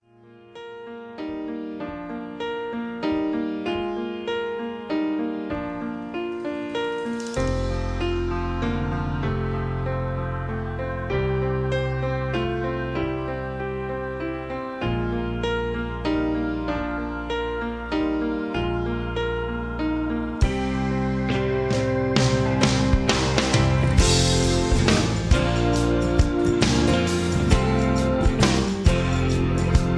(Key-F) Karaoke MP3 Backing Tracks
Just Plain & Simply "GREAT MUSIC" (No Lyrics).